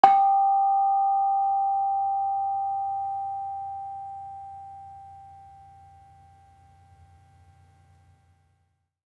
Bonang-G4-f.wav